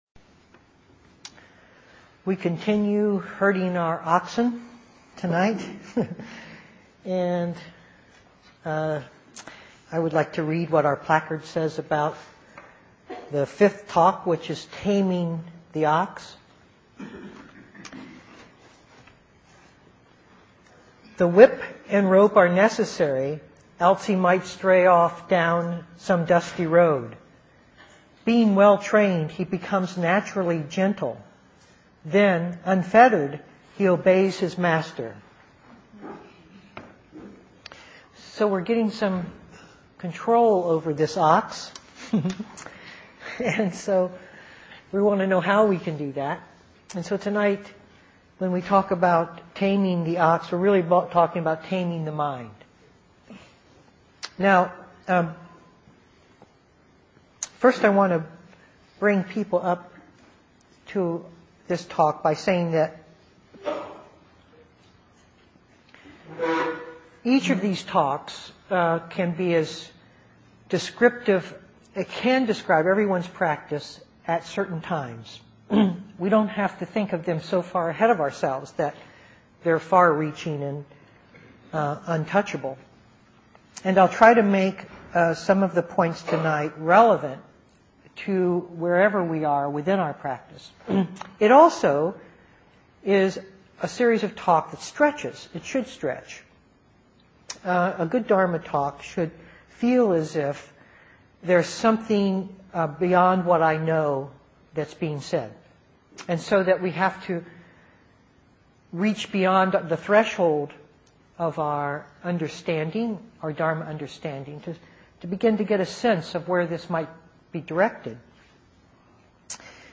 2008-06-24 Venue: Seattle Insight Meditation Center